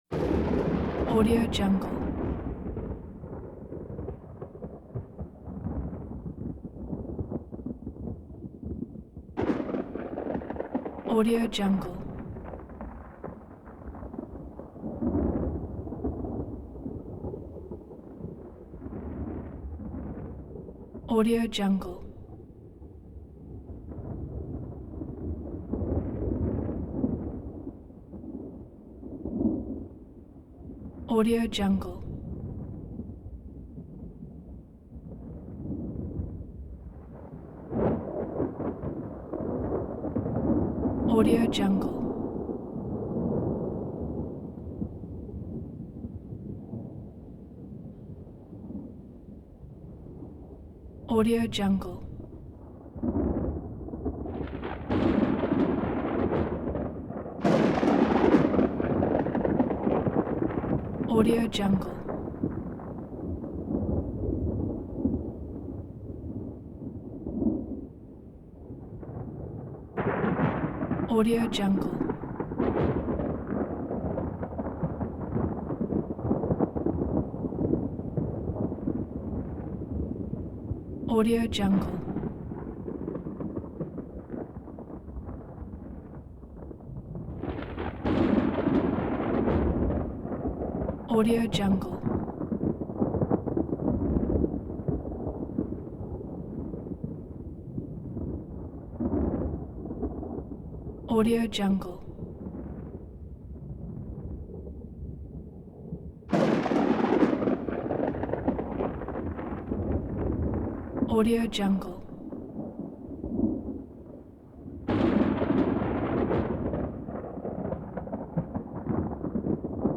دانلود افکت صدای غرش رعد و برق
افکت صدای غرش رعد و برق یک گزینه عالی برای هر پروژه ای است که به صداهای طبیعت و جنبه های دیگر مانند رعد و برق، صاعقه و تندر نیاز دارد.
Sample rate 16-Bit Stereo, 44.1 kHz
Looped No